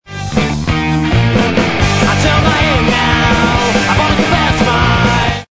punk-rock